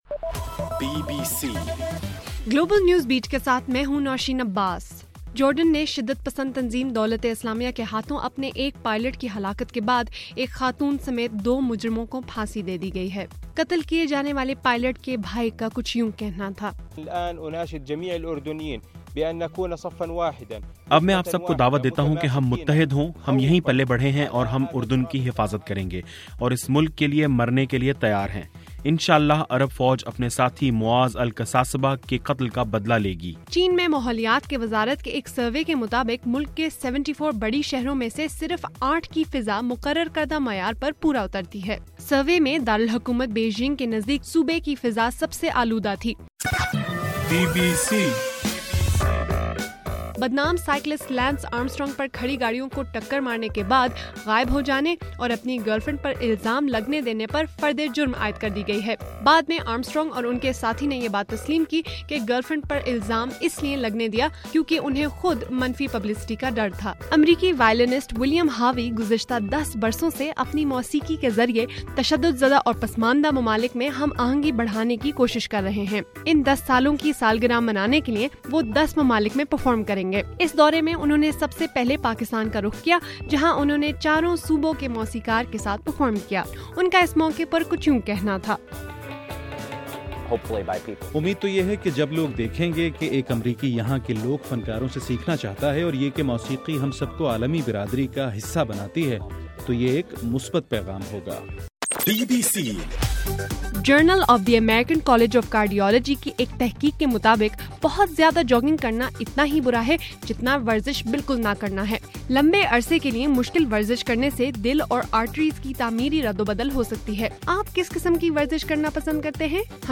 فروری 4: رات 8 بجے کا گلوبل نیوز بیٹ بُلیٹن